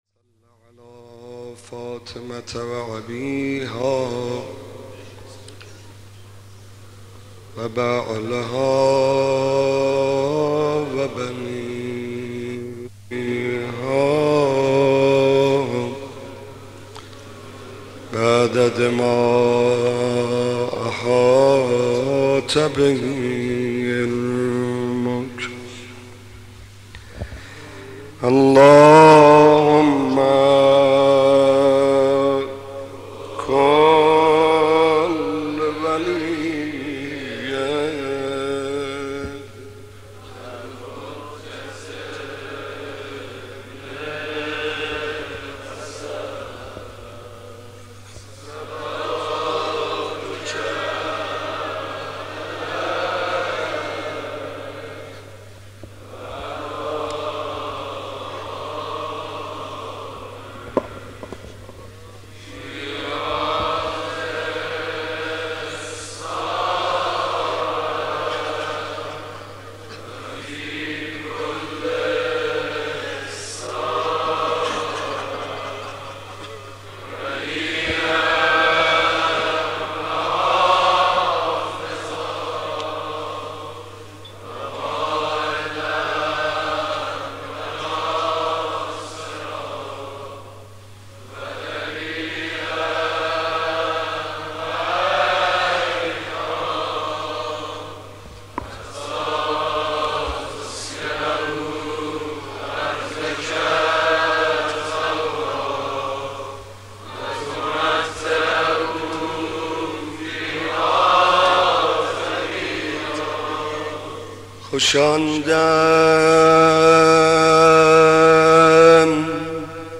مداح
مناسبت : شب چهارم محرم